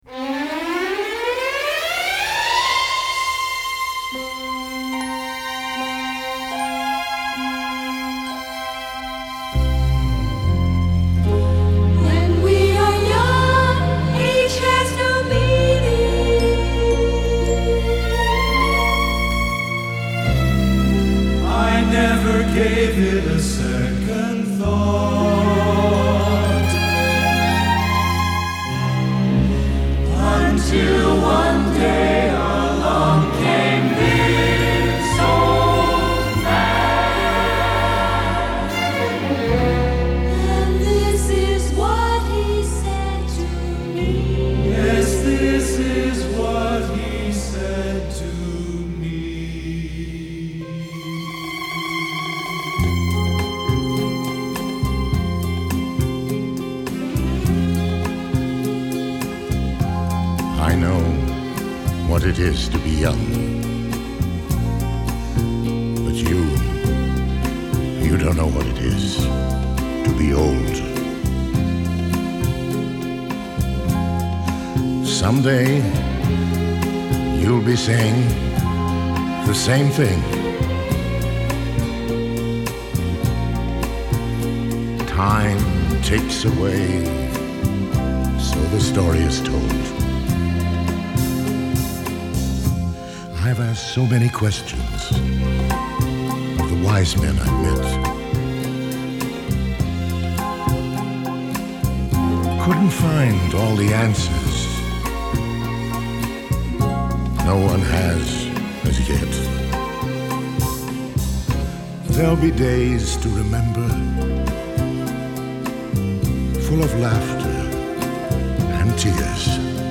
Голос просто завораживает!!!